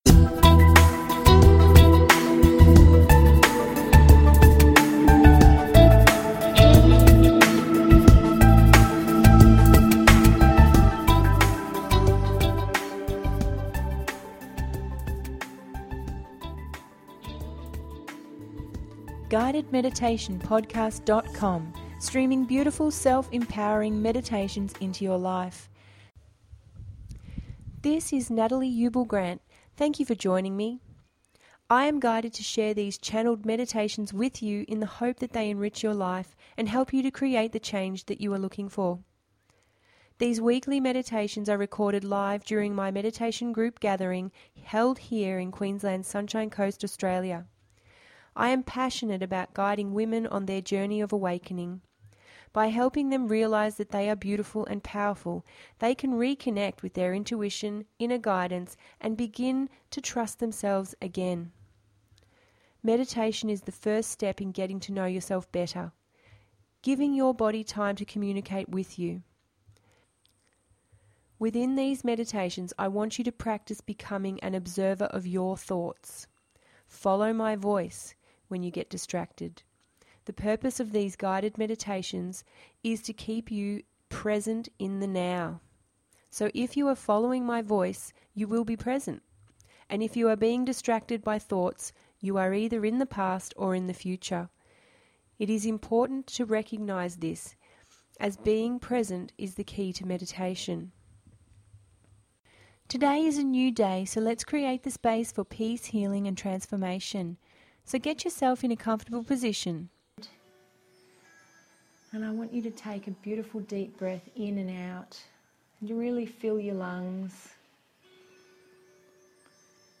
Balance Yin & Yan…ep 14 – GUIDED MEDITATION PODCAST